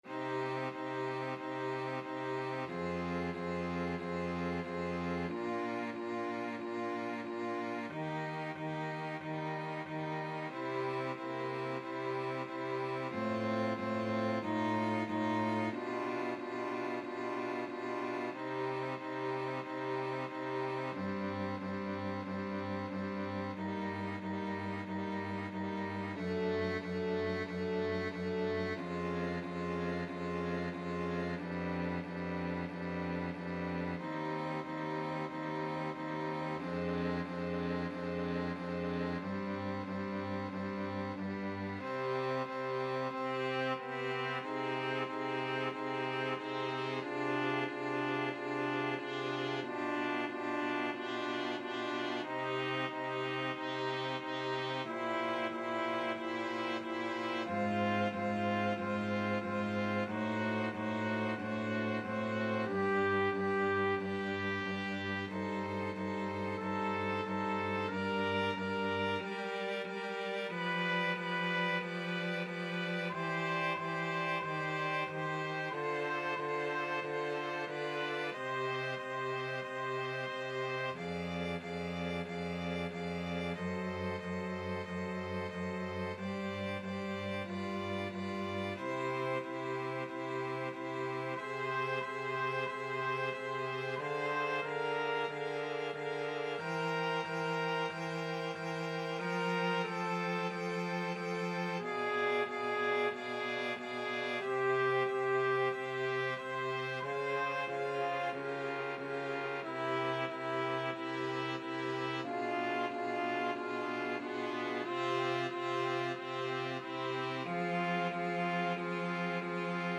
TrumpetBass Voice
Violin
Cello
2/2 (View more 2/2 Music)
Slow =c.92